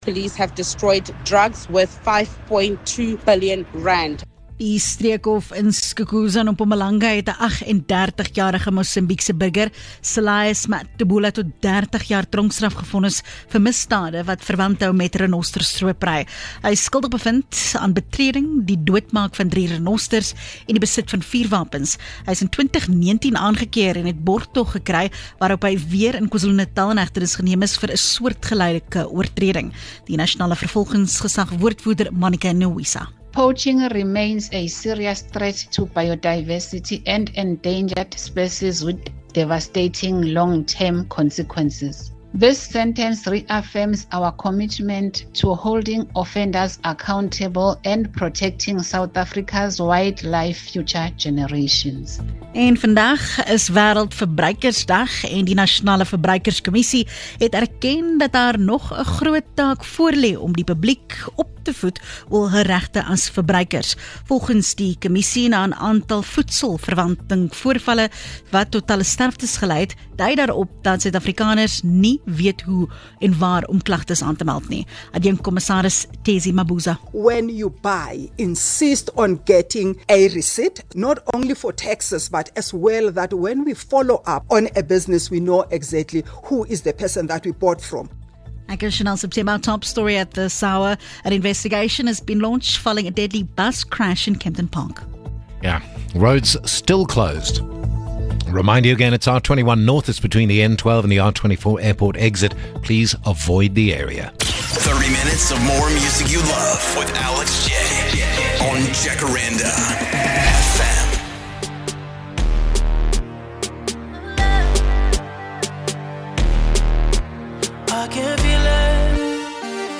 1 JacarandaFM News @ 14H02 4:30 Play Pause 24m ago 4:30 Play Pause Na później Na później Listy Polub Polubione 4:30 Here's your latest Jacaranda FM News bulletin.